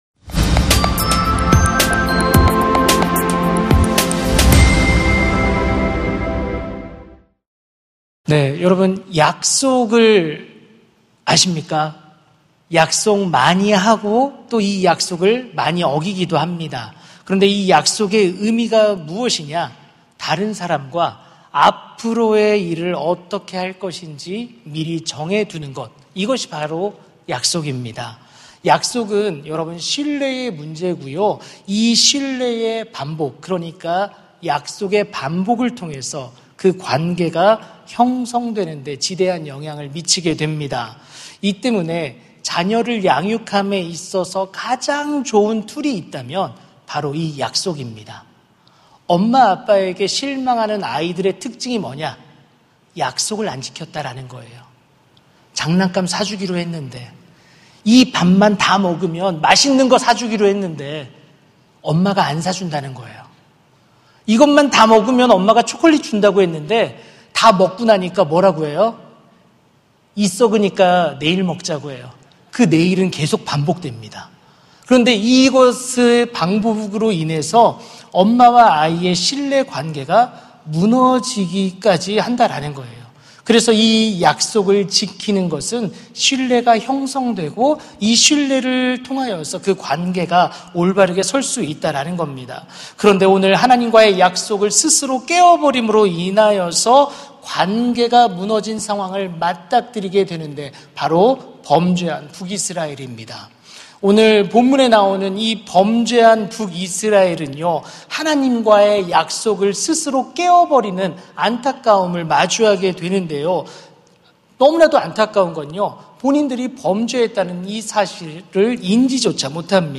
설교 : 금요심야기도회 (분당채플) 완전한 회복을 약속하셨다! 설교본문 : 호세아 1:10-2:1